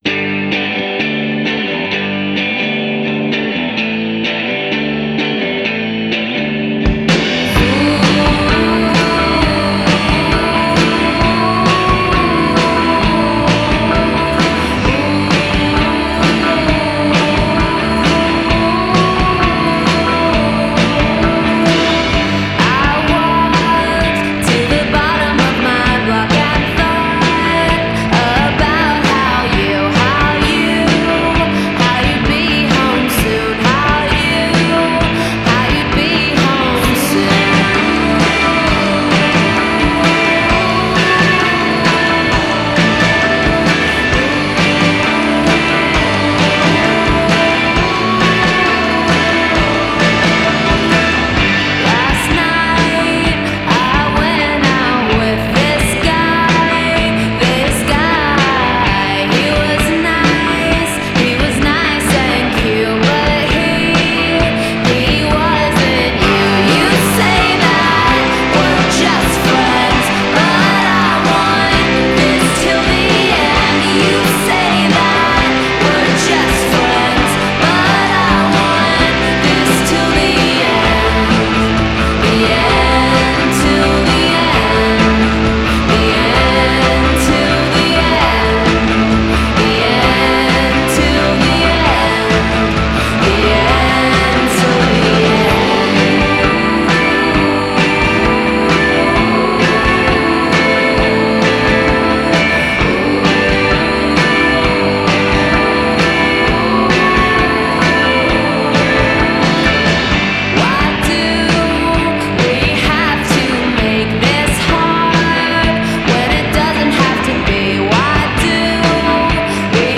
Hip fuzz rockers
This swinging track will definitely put a skip in your step.